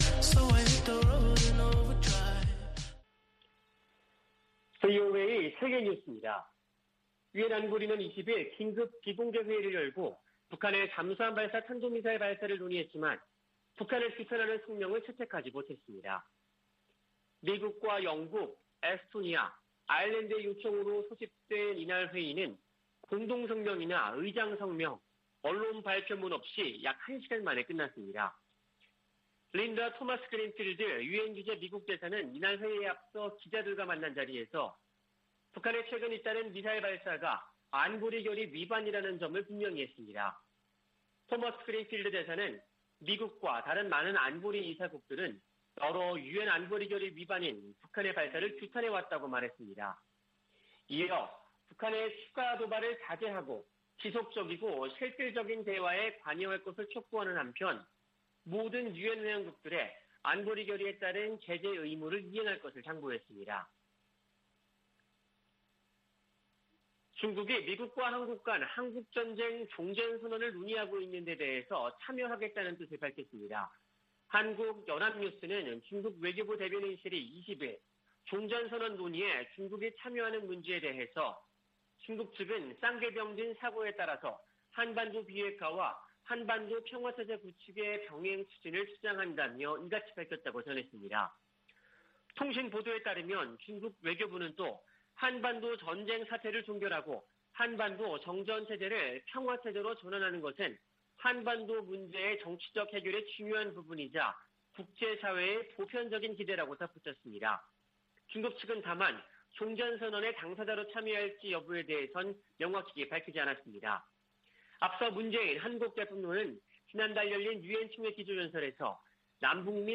VOA 한국어 아침 뉴스 프로그램 '워싱턴 뉴스 광장' 2021년 10월 22일 방송입니다. 유엔 안보리가 북한 SLBM 규탄 성명을 내는데 실패했습니다. 독일과 영국이 북한의 미사일 시험 발사를 규탄하며, 완전하고 검증 가능하며 되돌릴 수 없는 핵포기를 촉구했습니다. 북한은 SLBM 시험발사가 미국을 겨냥한 게 아니라고 주장했습니다.